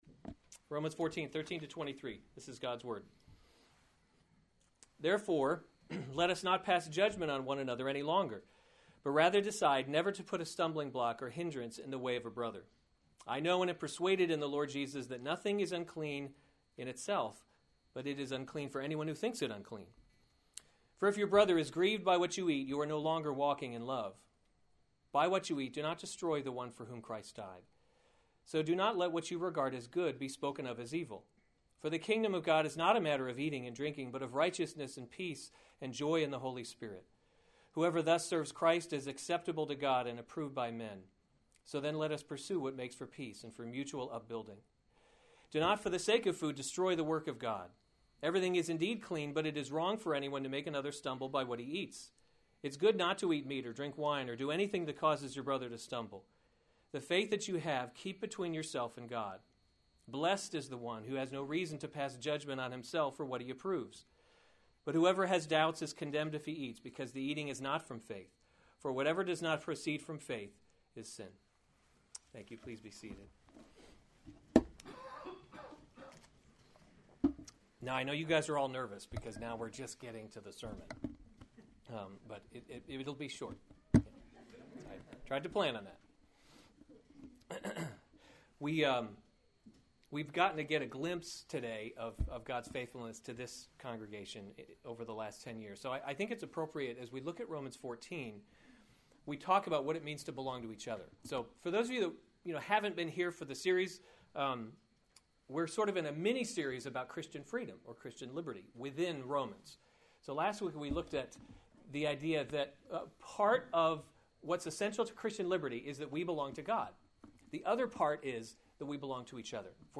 April 18, 2015 Romans – God’s Glory in Salvation series Weekly Sunday Service Save/Download this sermon Romans 14:13-23 Other sermons from Romans Do Not Cause Another to Stumble 13 Therefore let […]